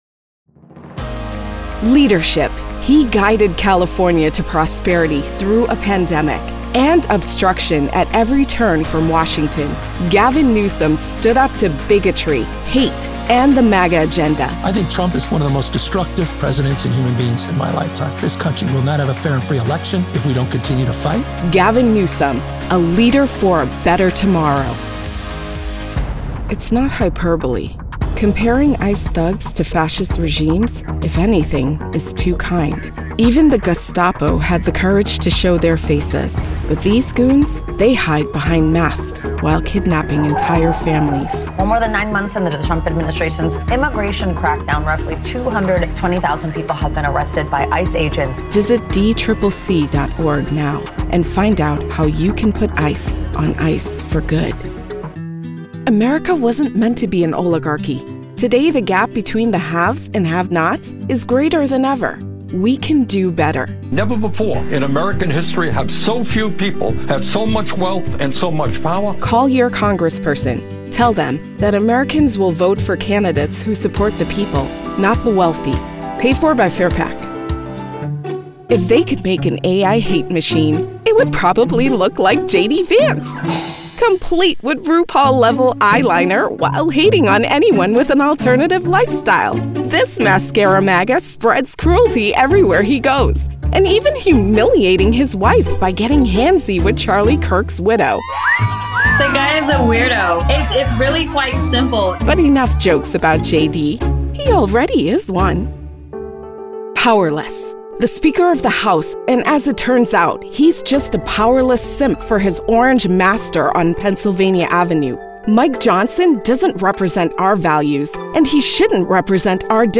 Political Voiceover Demo